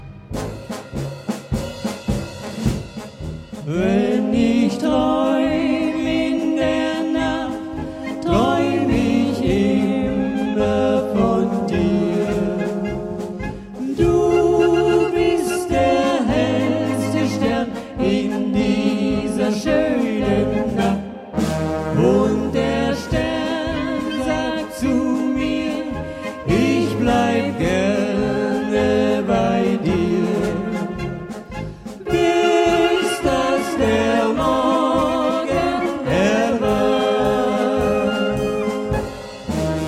Live-Mitschnitt 4 (unbearbeitet)